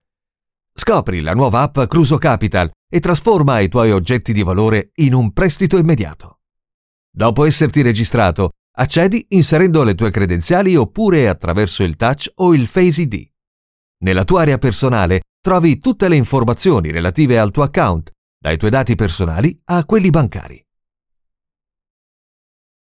Natürlich, Vielseitig, Zuverlässig, Erwachsene, Sanft
Kommerziell